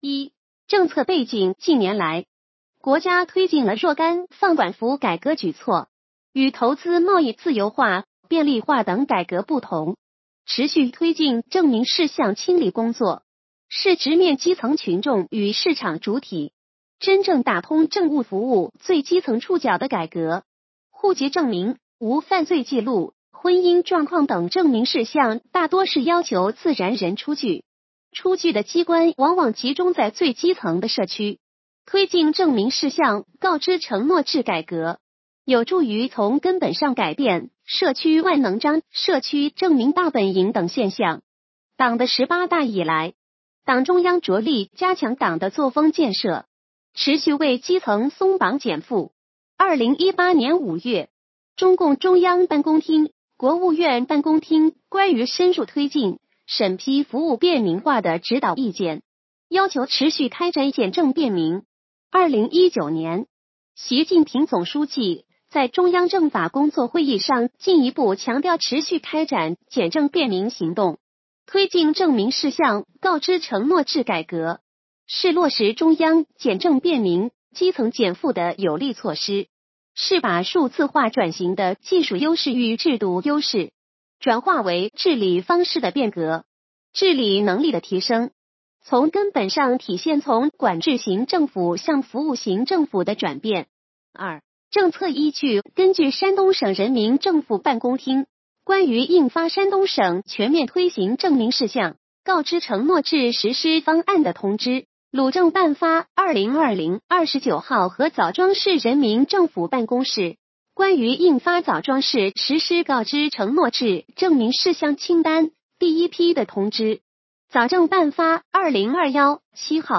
语音解读